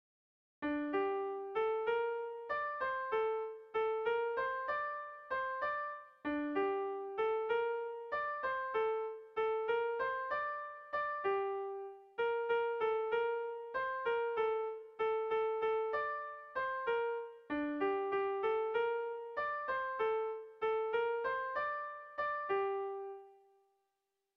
Zortziko txikia (hg) / Lau puntuko txikia (ip)
A1A2BA2